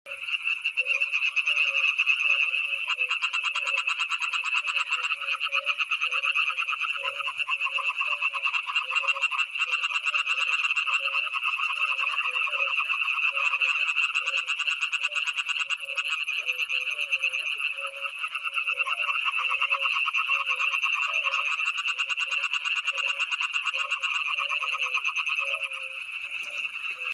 Data resource Xeno-canto - Anura sounds from around the world